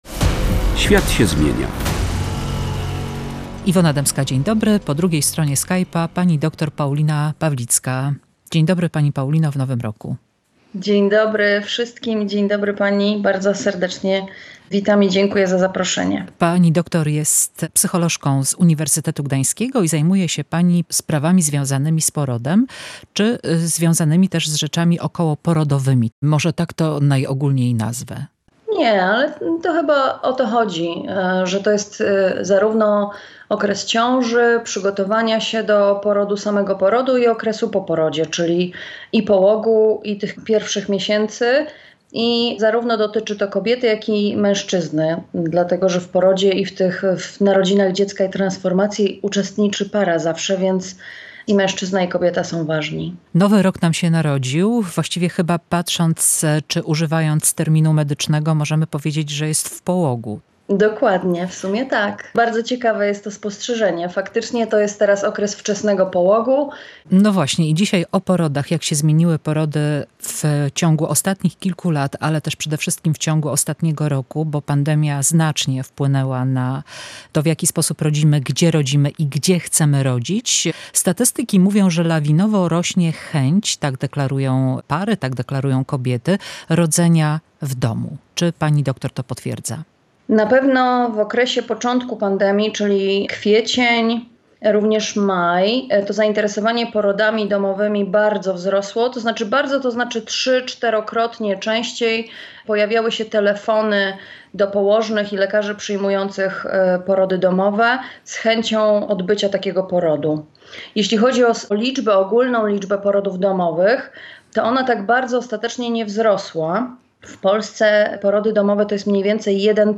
Porody w czasach pandemii – rozmowa